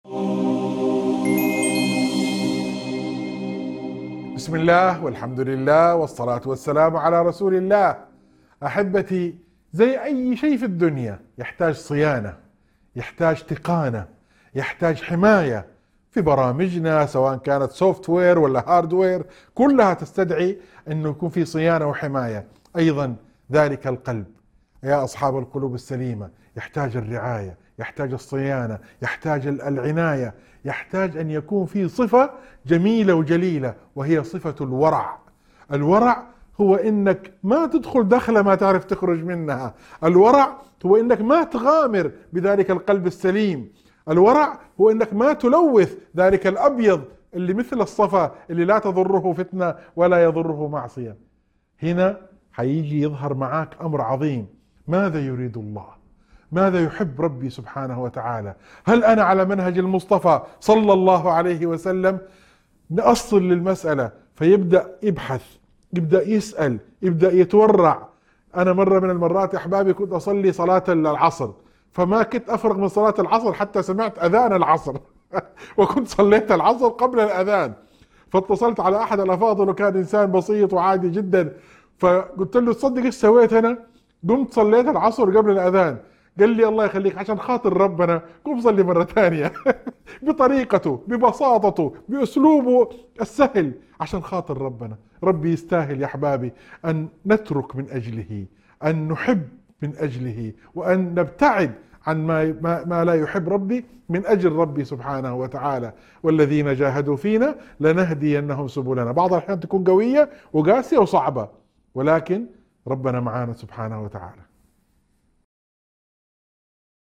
موعظة مؤثرة تركز على أهمية صيانة القلب وحمايته من خلال الورع ومراقبة الله في كل الأمور. يتحدث المتحدث عن تجربة شخصية في الصلاة ليعزز معنى الصدق مع الله، ويحث على الجهاد في سبيله وترك ما لا يحب.